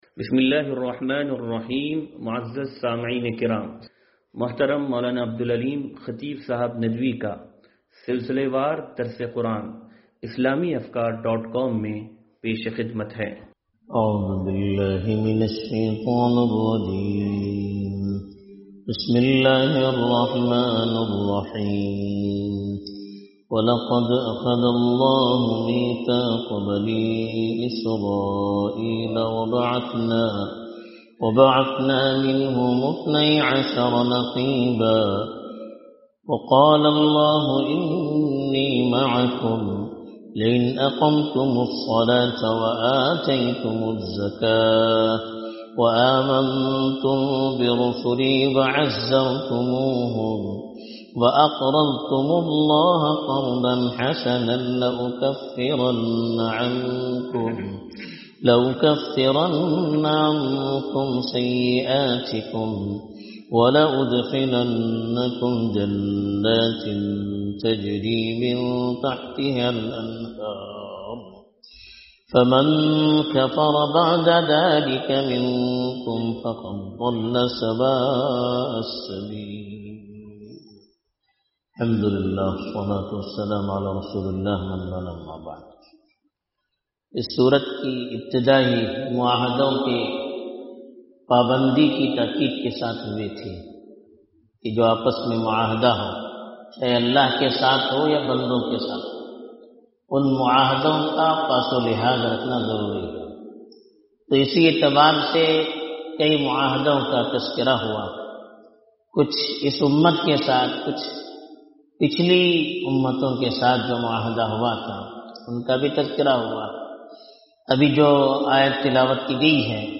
درس قرآن نمبر 0438